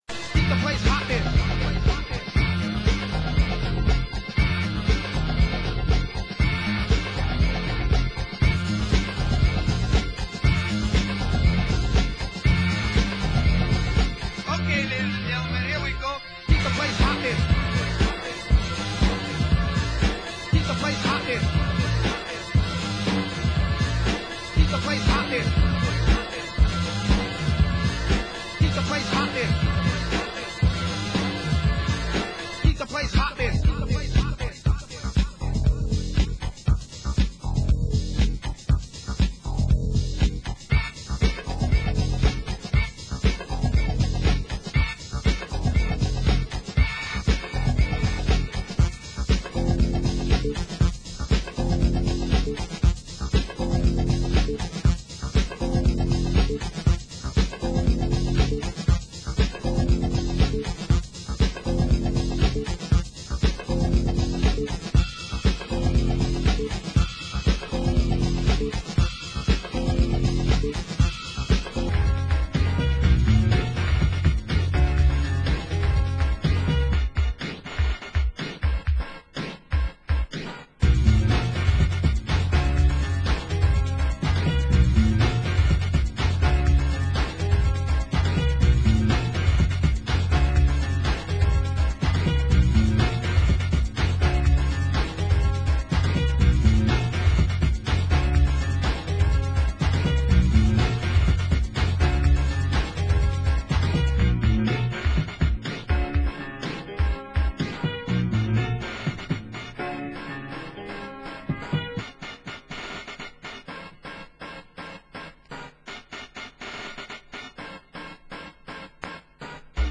Genre: Break Beat